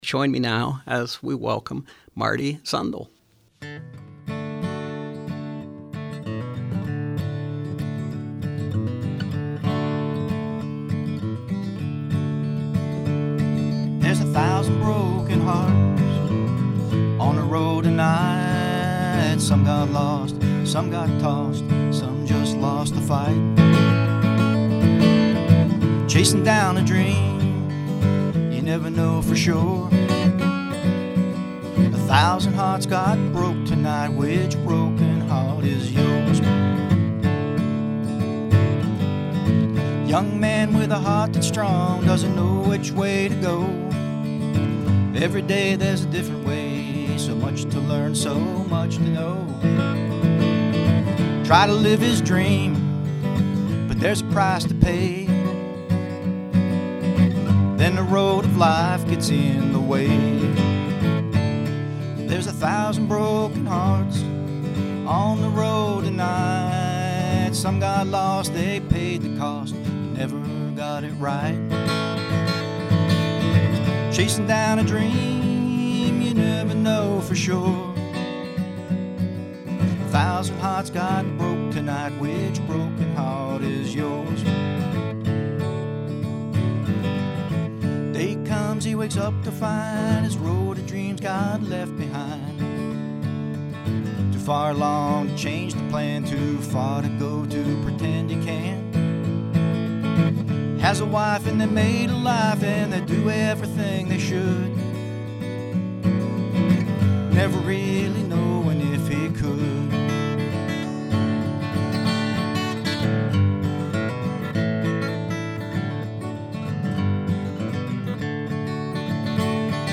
Country, bluegrass and old-time music